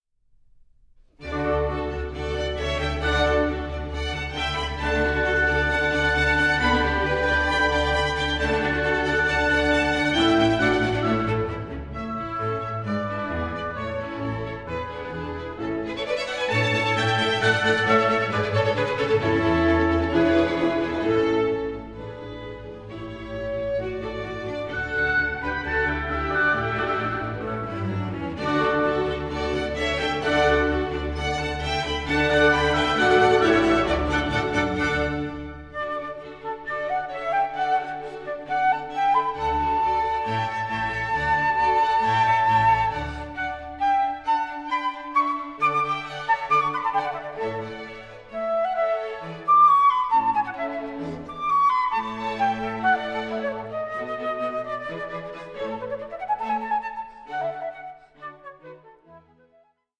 Flute Concerto in D major